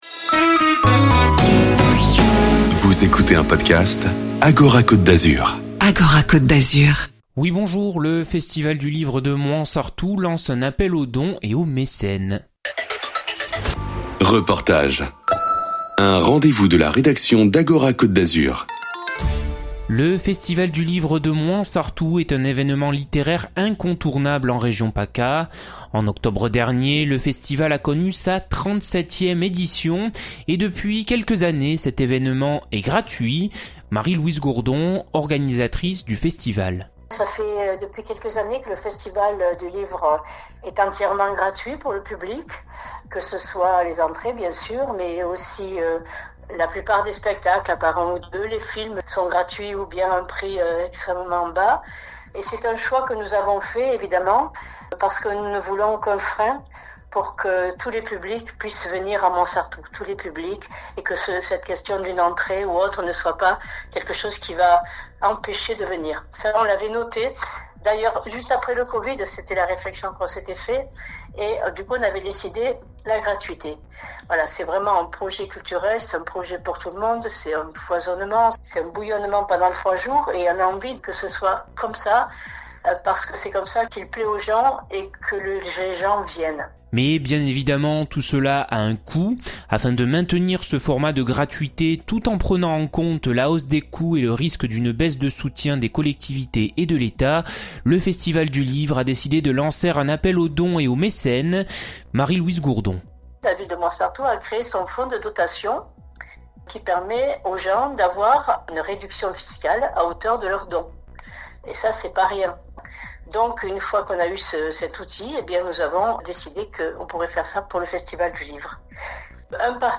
REPORTAGES ACTU et SOCIETE